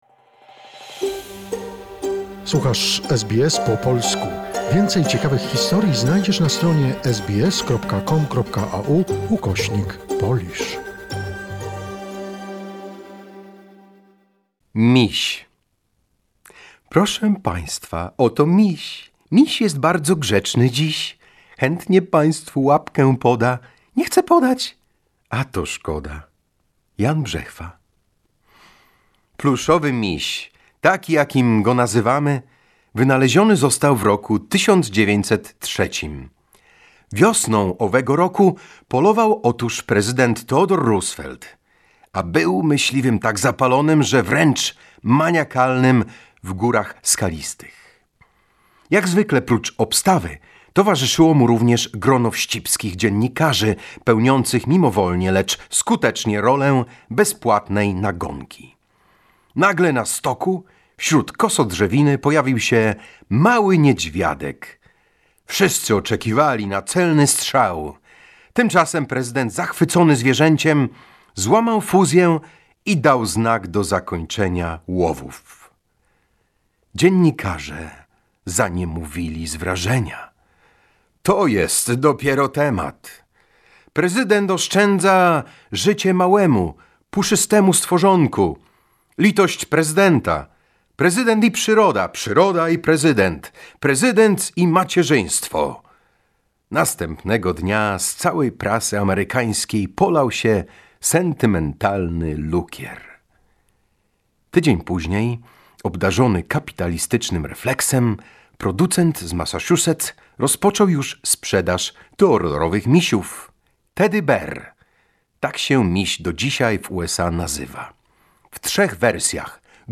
Archival recordings.